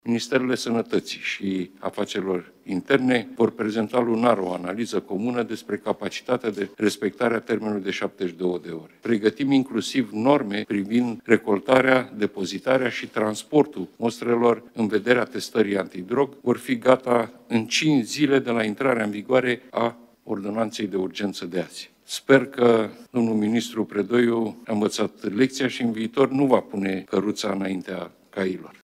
Ordonanța de Urgență 84 privind siguranța rutieră va fi modificată, astfel încât șoferii care ies pozitivi la testarea pentru alcool sau droguri și cei care refuză testarea își vor primi permisul înapoi în cel mult 72 de ore dacă analizele de sânge nu sunt gata în acest interval, a declarat premierul Marcel Ciolacu, joi, 11 iulie, la începutul ședinței de Guvern.